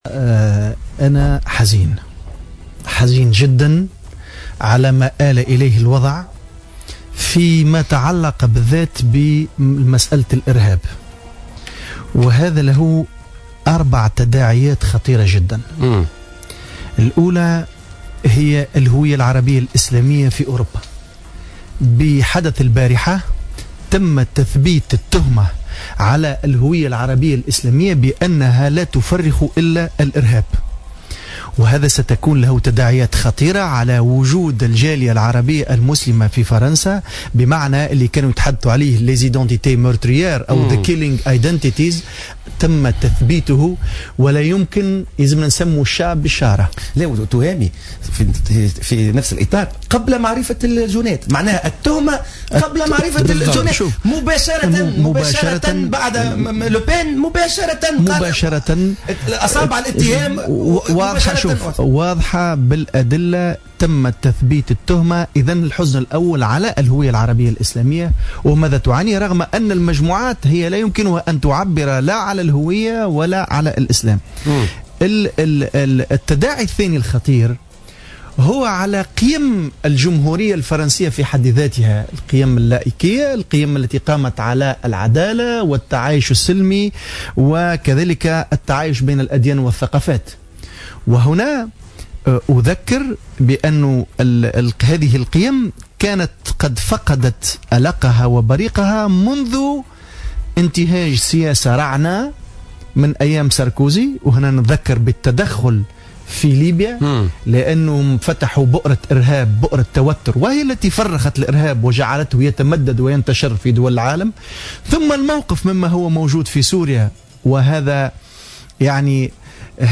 قال نائب مجلس الشعب التوهامي العبدولي ضيف برنامج "بوليتيكا" اليوم الخميس إن الهجوم المسلّح الذي استهدف أمس مقر الصحيفة الفرنسية "شارل ايبدو" سيكون له تداعيات خطيرة على الجالية الإسلامية المقيمة في فرنسا.